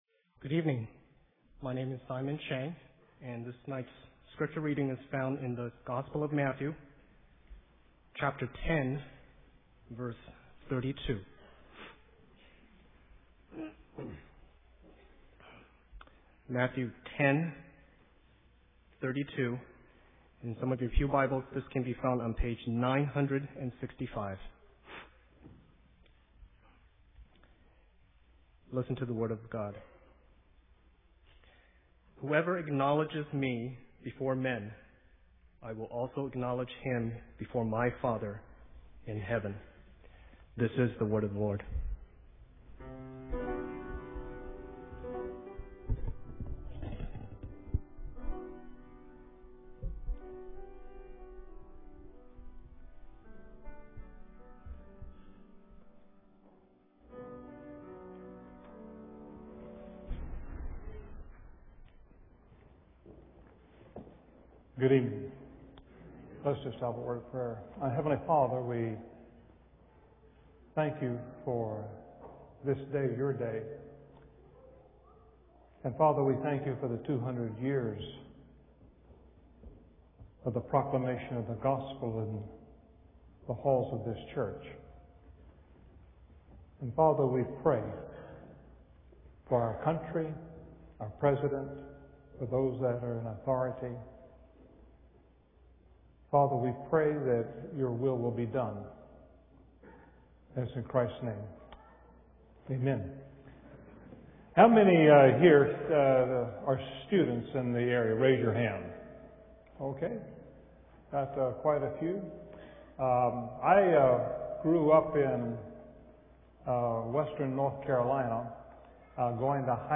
A message from the series "Special Speakers."